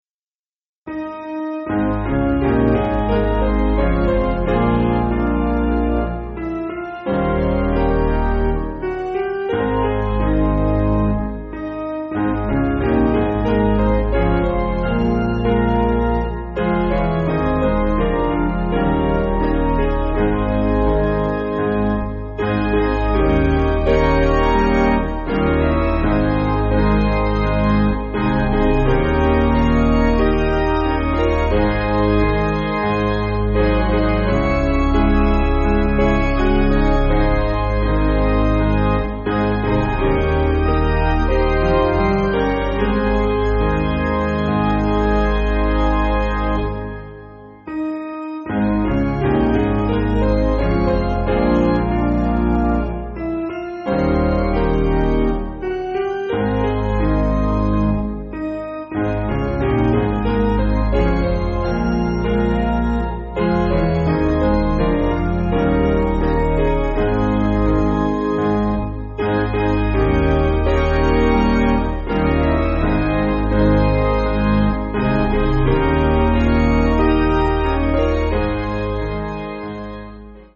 Basic Piano & Organ
(CM)   3/Ab